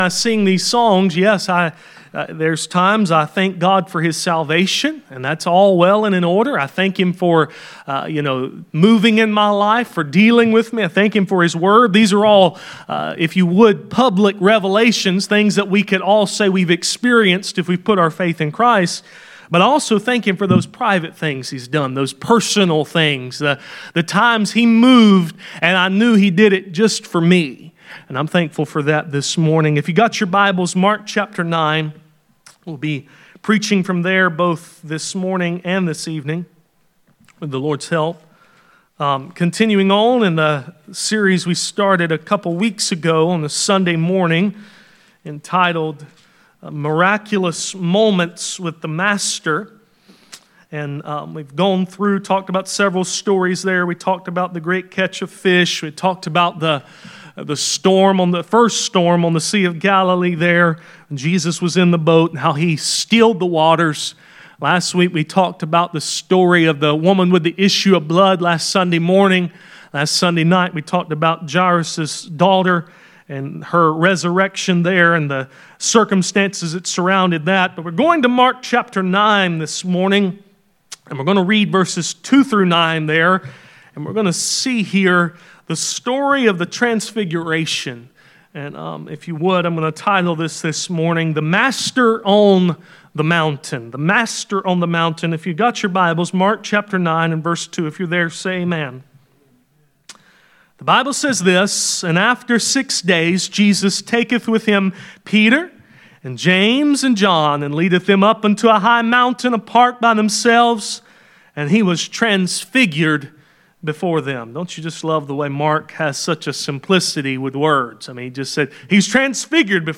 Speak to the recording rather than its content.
Mark 9:2-9 Service Type: Sunday Morning « Joshua